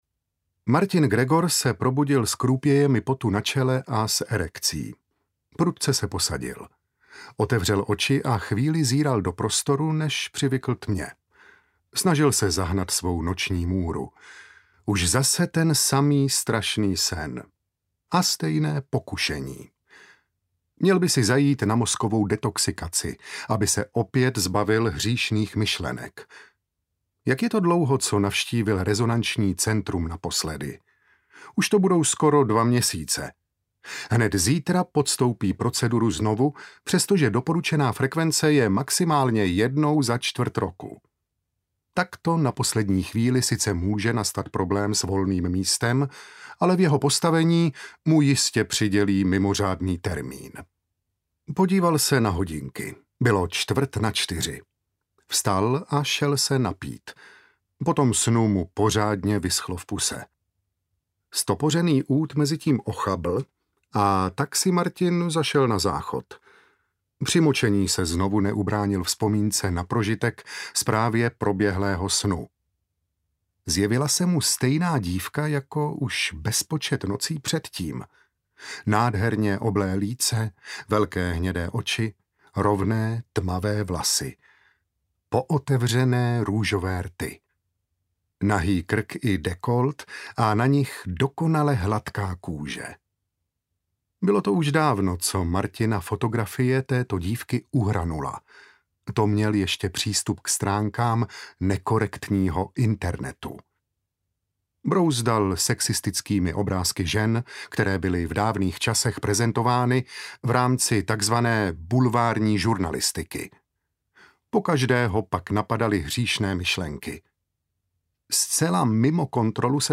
Epizody 2084 audiokniha
Ukázka z knihy
epizody-2084-audiokniha